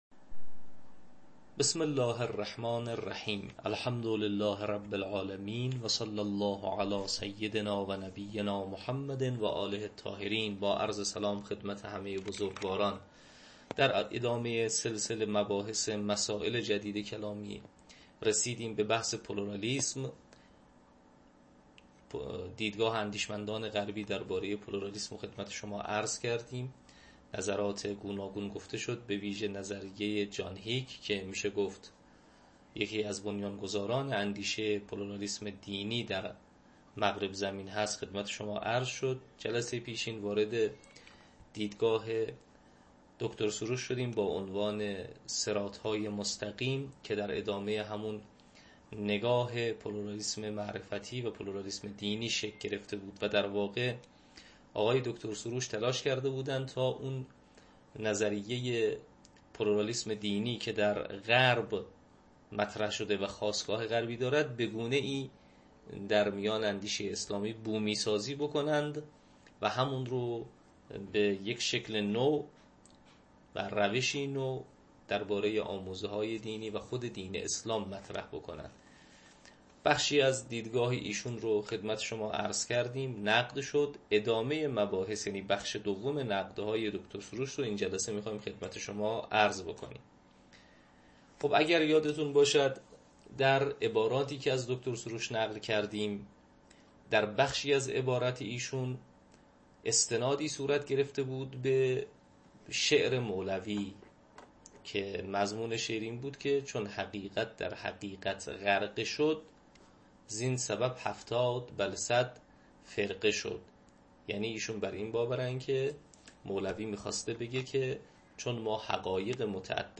تدریس کلام جدید